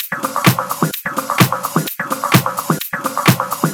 VEH1 Fx Loops 128 BPM
VEH1 FX Loop - 34.wav